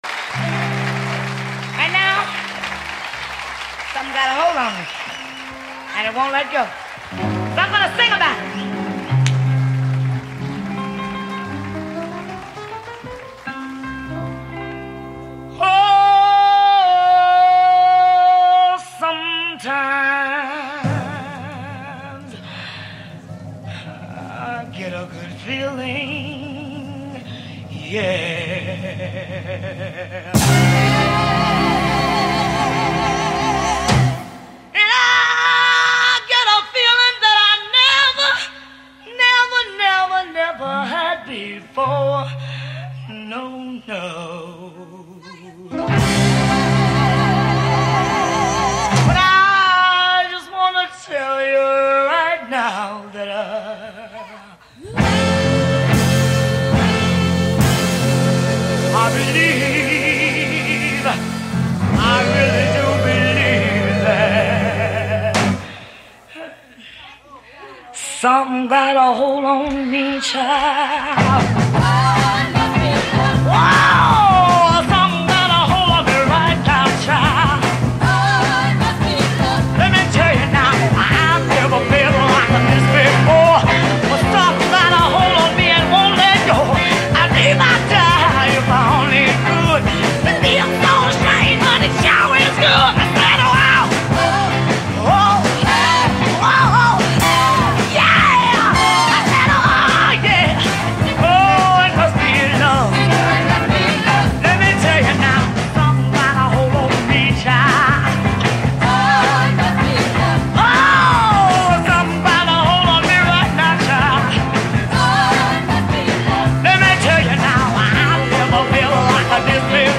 live in texas, 1964